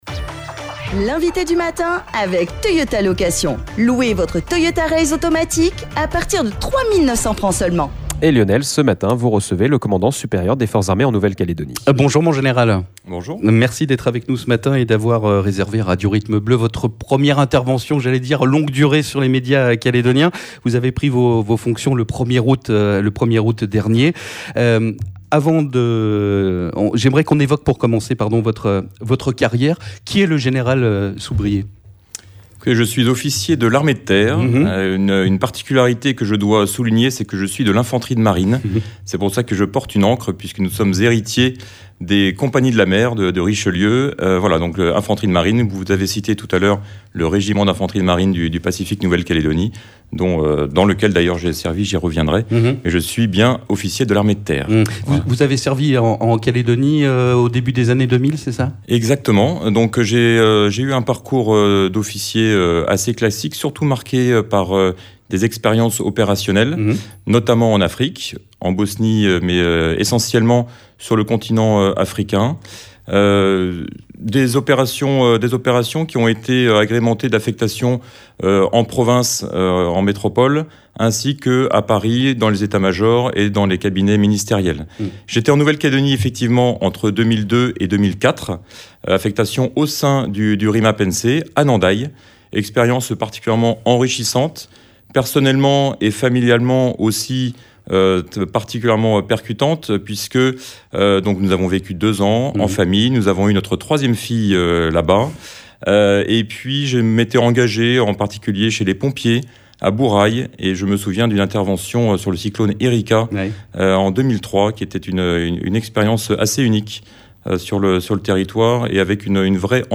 Première interview dans les médias calédoniens, depuis sa prise de fonction le 1er aout dernier, du général de brigade Soubrier, commandant supérieur des forces armées en nouvelle Calédonie (FANC)